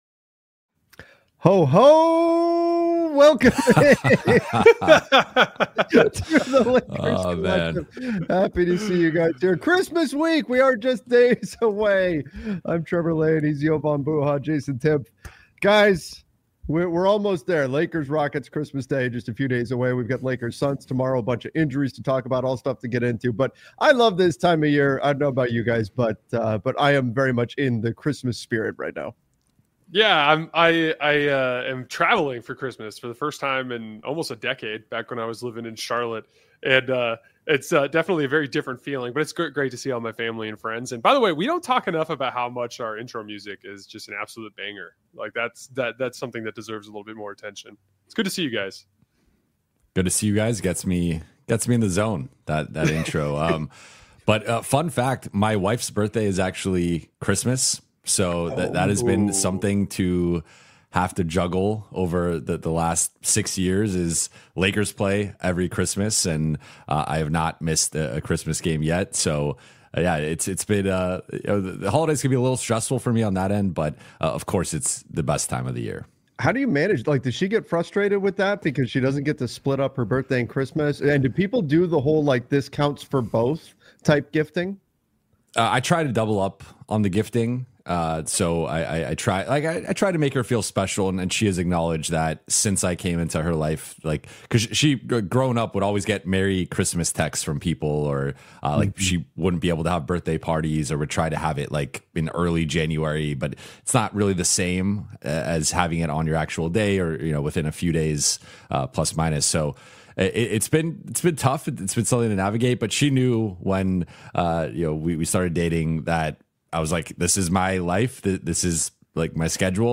Welcome to the Lakers Collective — a weekly Los Angeles Lakers roundtable